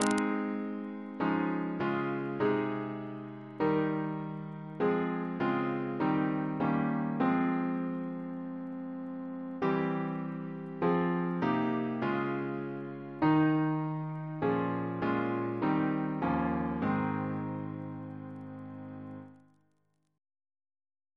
Double chant in A♭ Composer: Chris Biemesderfer (b.1958) Note: for Psalm 91